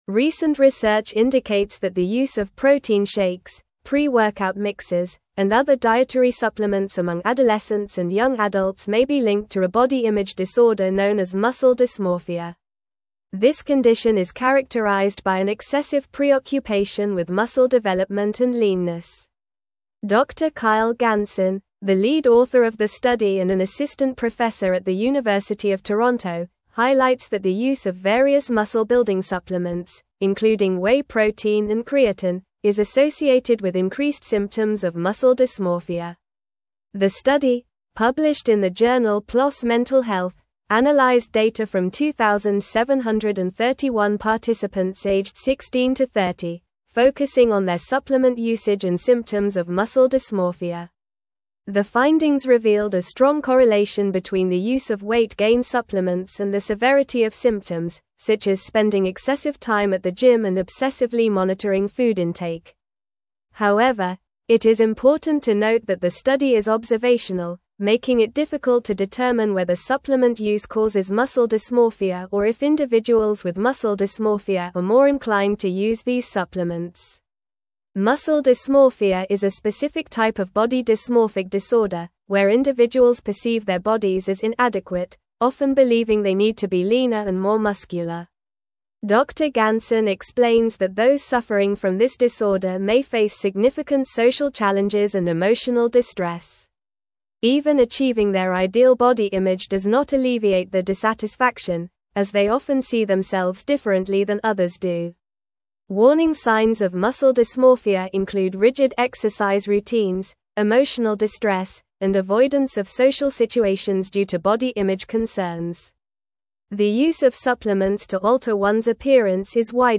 Normal
English-Newsroom-Advanced-NORMAL-Reading-Link-Between-Supplements-and-Muscle-Dysmorphia-in-Youth.mp3